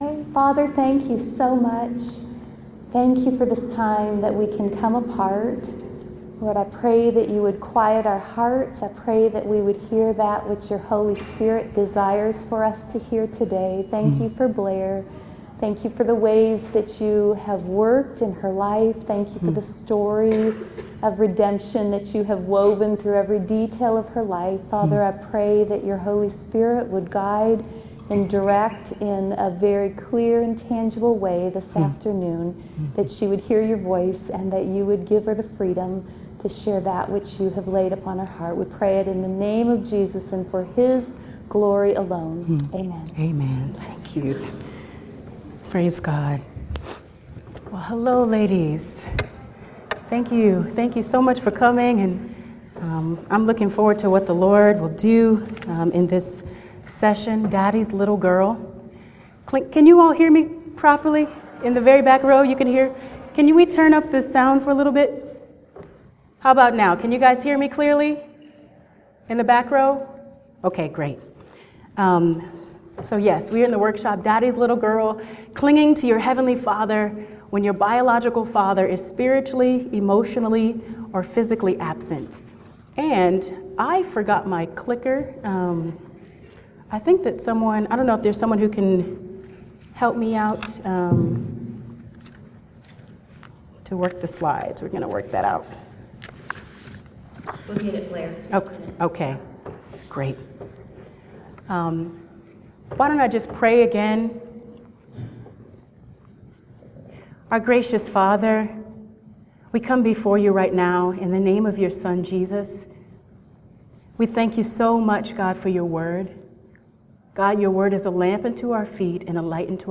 Daddy's Little Girl | True Woman '14 | Events | Revive Our Hearts
In this workshop you will see how sin has negatively affected many women's relationships with their biological fathers. You'll also explore how the gospel is able to heal and restore you as you embrace your new identity as an adopted daughter of God.